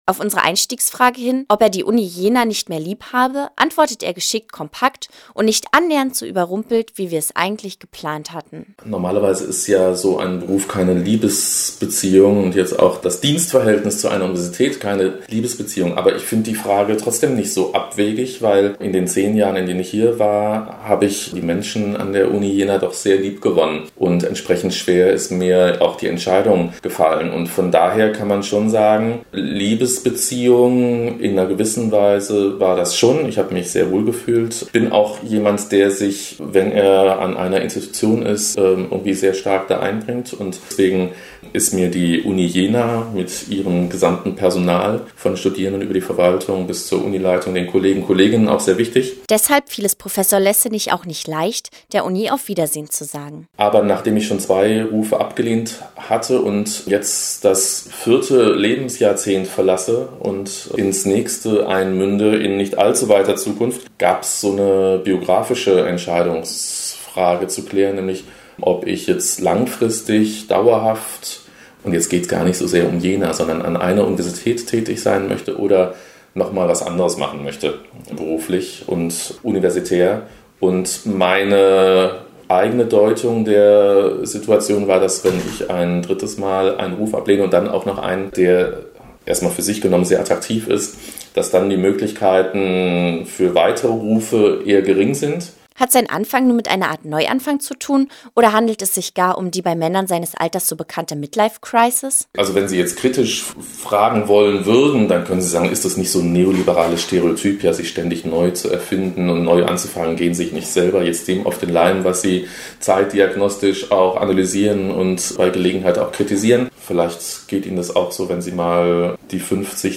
Wir wollen’s persönlicher: Professor Lessenich spricht mit uns über Liebe, Midlifecrisis und neoliberale Stereotype.